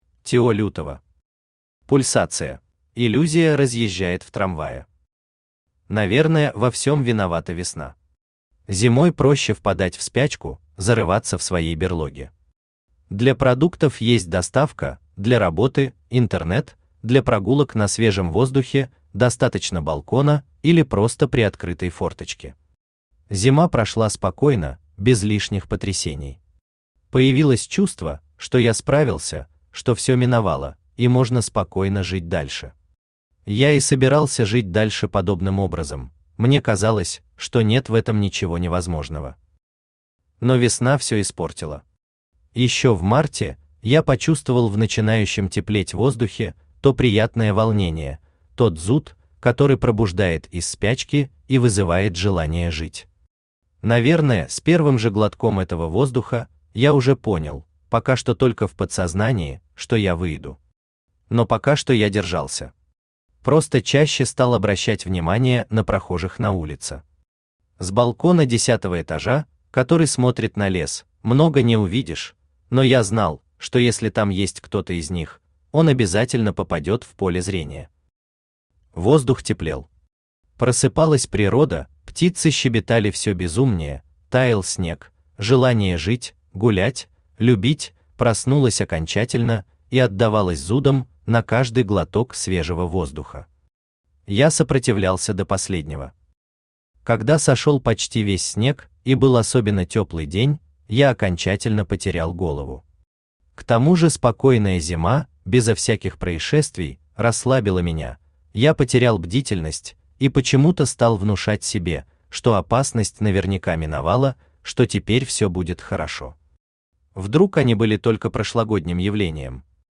Аудиокнига Пульсация | Библиотека аудиокниг
Aудиокнига Пульсация Автор Тео Лютова Читает аудиокнигу Авточтец ЛитРес.